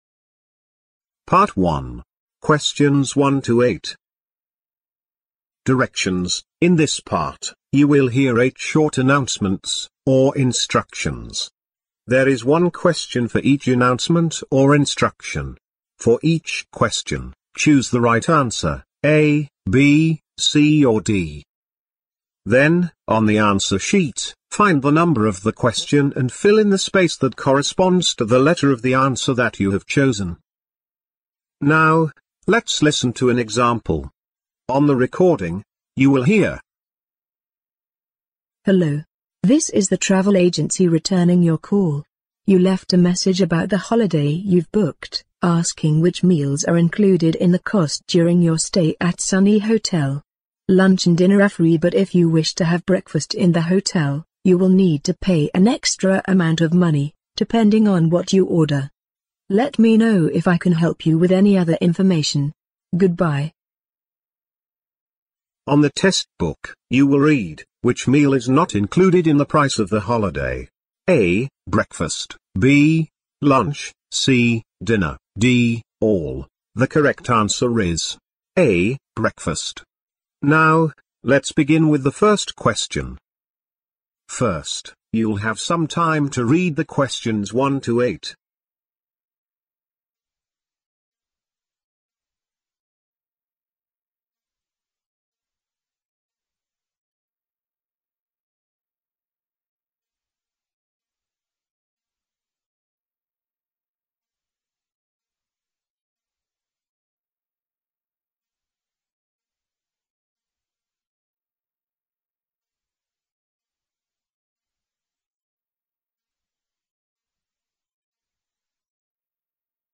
Directions: In this part, you will hear EIGHT short announcements or instructions.